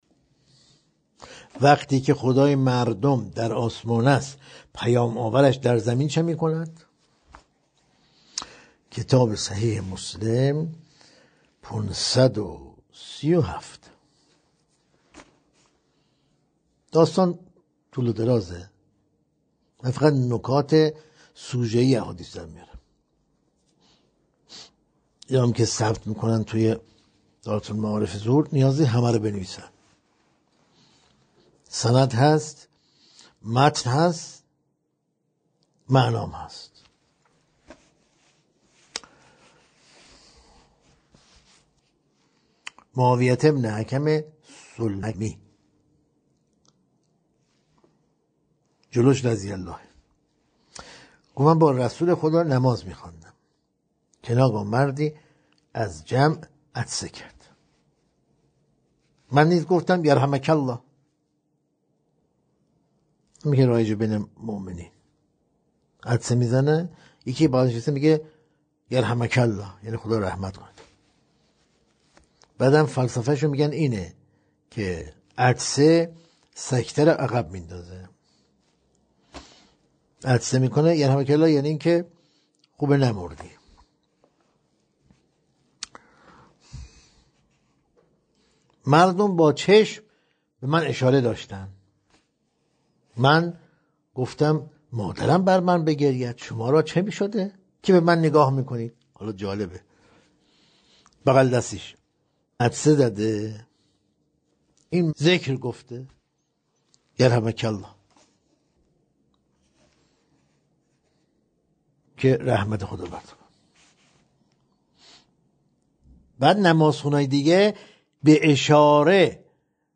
گزیده‌ای از تدریس‌های روزانه